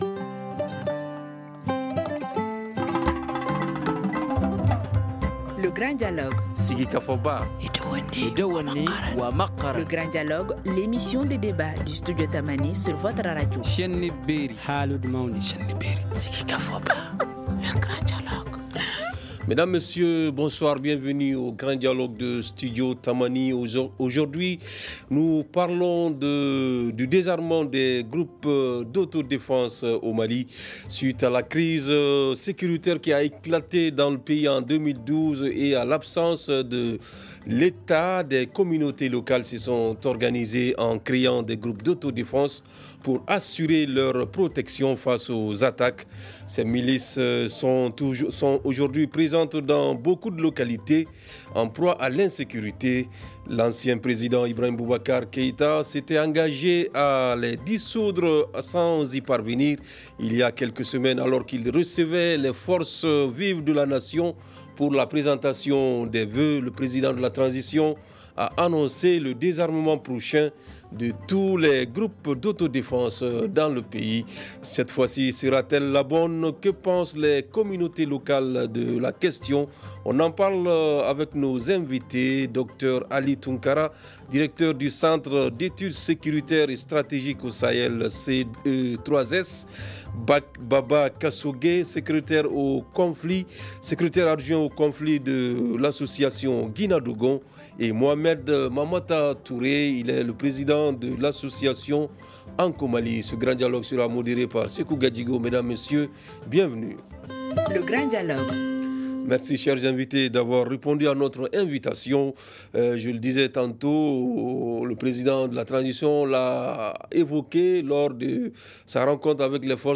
Écoutez l’ intégralité de l’émission Grand Dialogue :